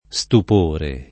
stupore [ S tup 1 re ] s. m.